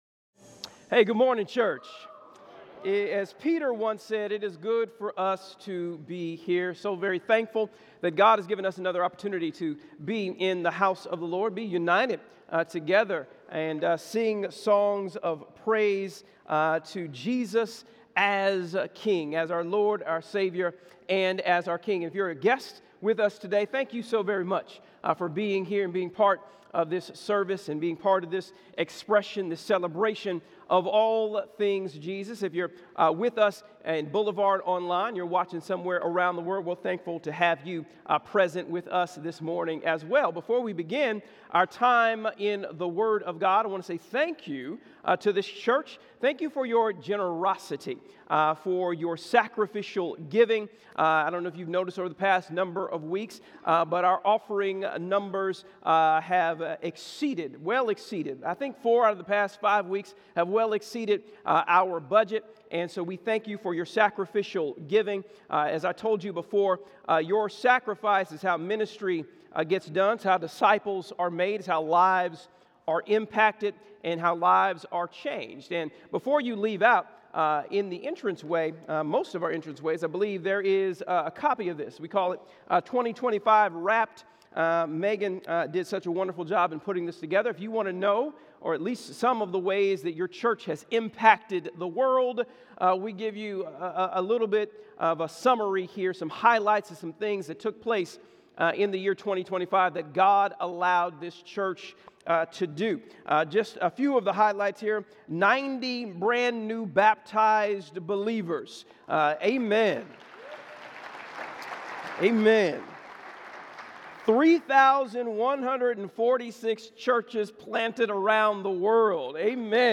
All Sermons SERMON AUDIO